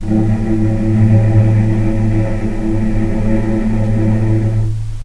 healing-soundscapes/Sound Banks/HSS_OP_Pack/Strings/cello/ord/vc-A2-pp.AIF at 48f255e0b41e8171d9280be2389d1ef0a439d660
vc-A2-pp.AIF